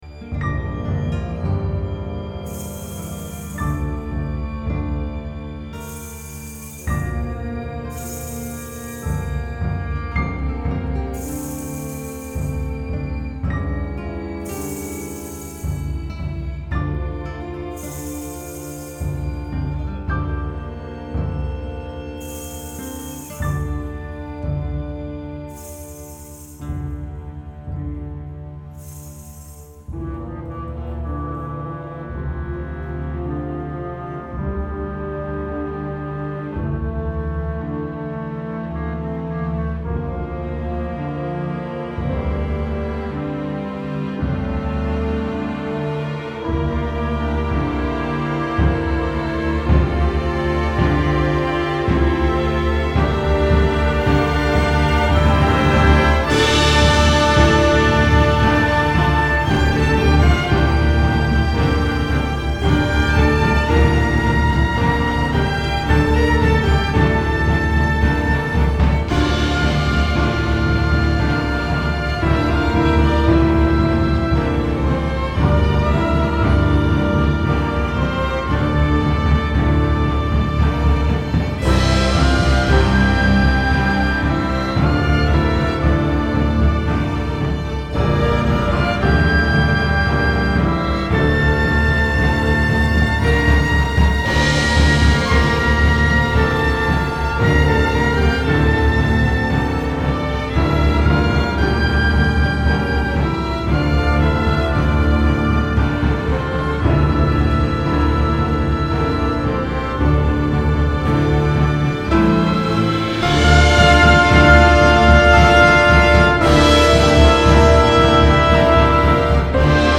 Музыка для спектакля. В аудио можно послушать, пока что, фрагменты сухих исходников.